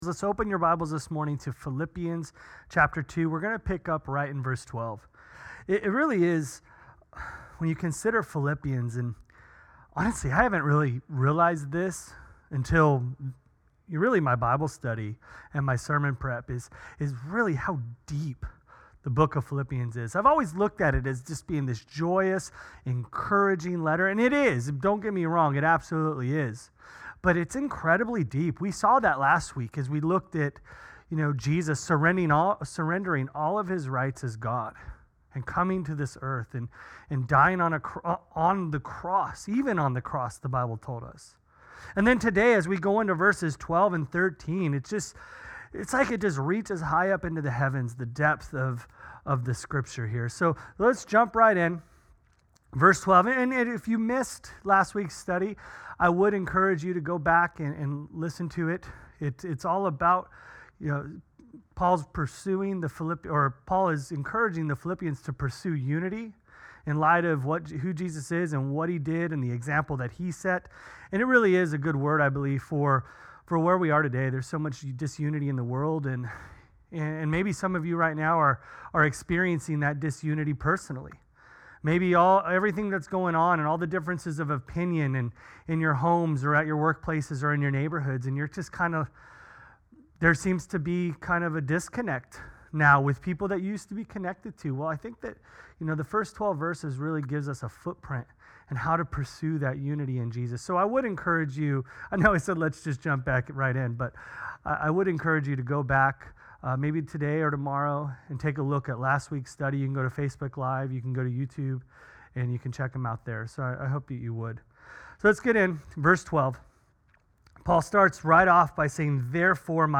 Sermon Series – Calvary Chapel West Ashley